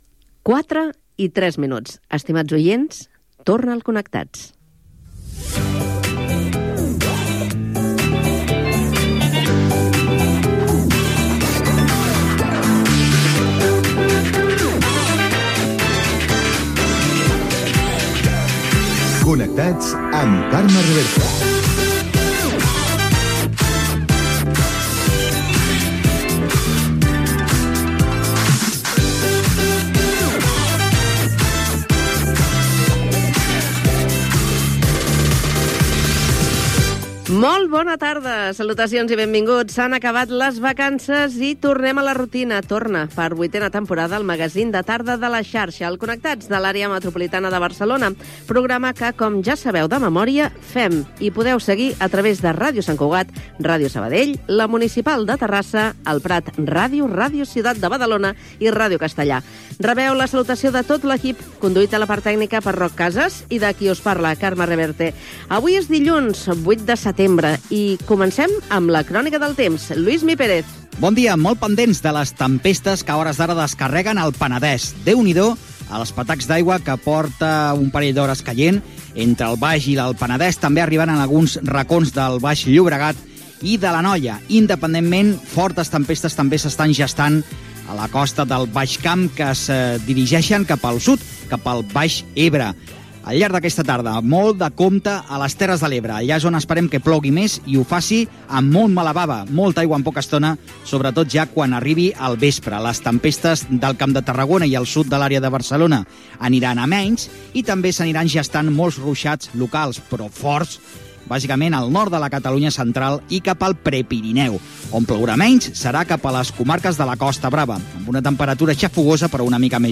Hora, careta, presentació del programa en l'inici de la vuitena temporada d'emissió. Connexió de les emissores de l'àrea metropolitana de Barcelona. Informació del temps i sumari del programa. Indicatiu. Informacions des de diverses emissores.
Entreteniment
FM